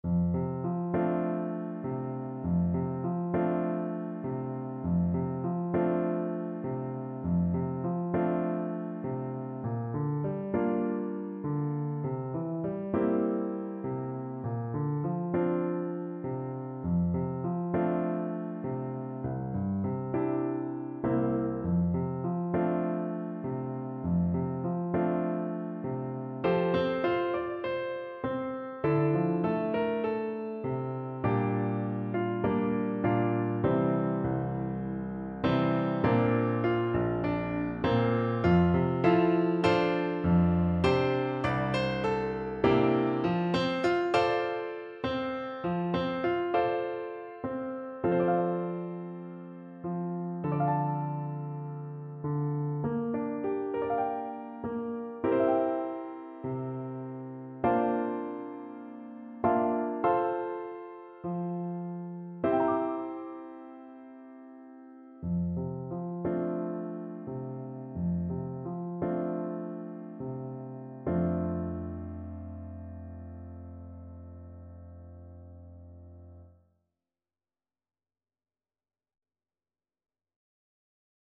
4/4 (View more 4/4 Music)
Gently =c.100